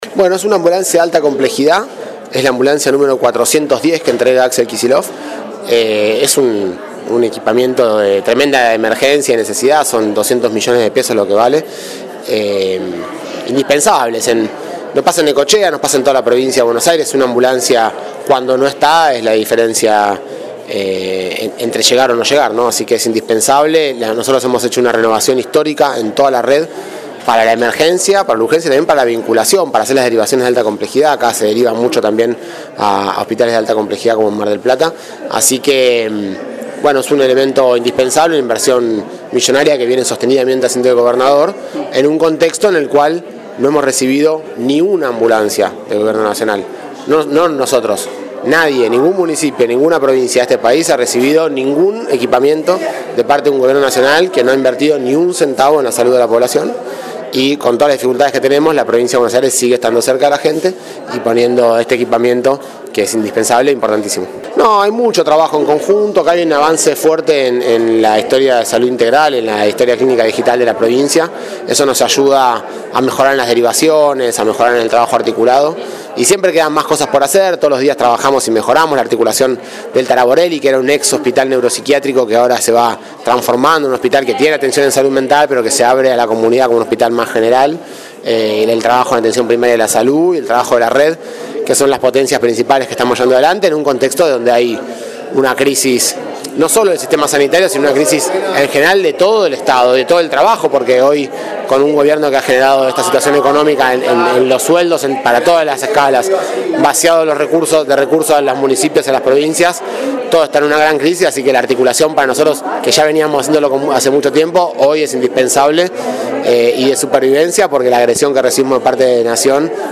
La entrega del móvil se dio en el segundo punto de la recorrida hecha por Kicillof y su comitiva, en el Centro Integral Municipal del Barrio Municipal, en calle 79 Nº 3740.